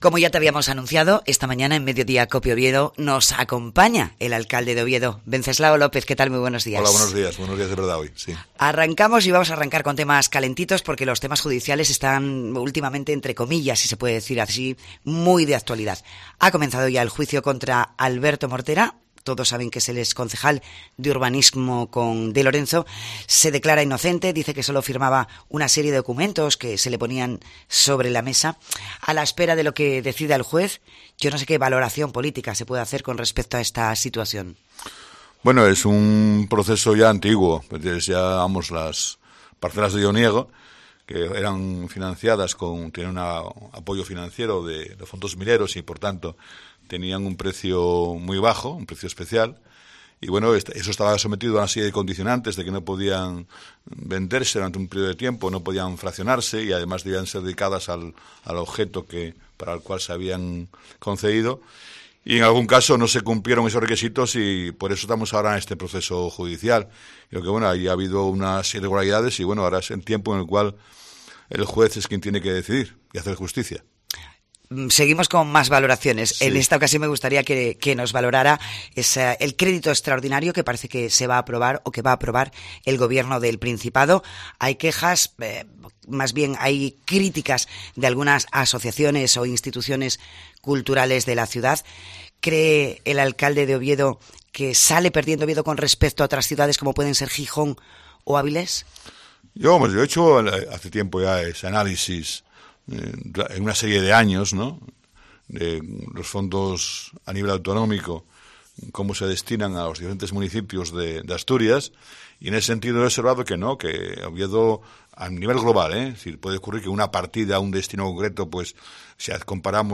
El alcalde de Oviedo niega, en una entrevista concedida a COPE, que el Principado discrimine, con sus presupuestos, a la capital de Asturias.
El alcalde de Oviedo responde a las preguntas de COPE Oviedo
El alcalde de Oviedo ha estado, este martes, en los estudios de COPE Oviedo. Wenceslao López ha repasado, en La Mañana, algunos de los temas que marcan la actualidad en la capital de Asturias.